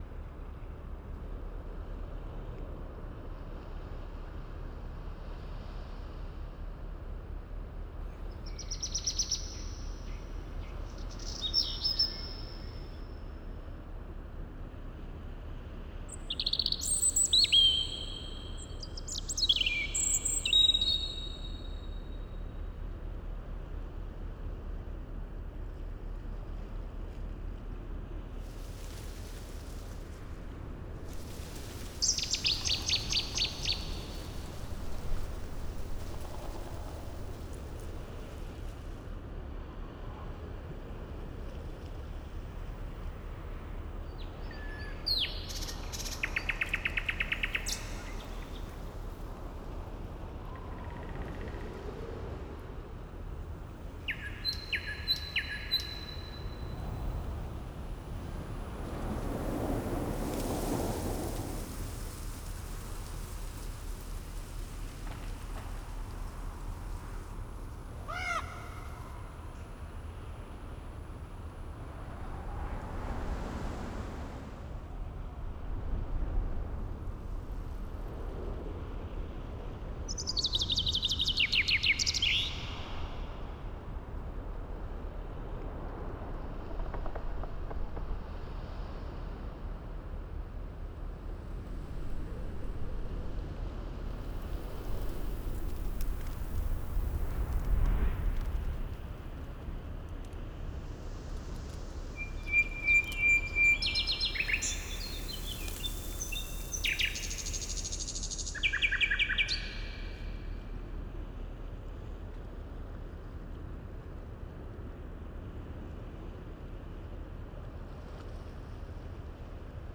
ForestAtmos_02.wav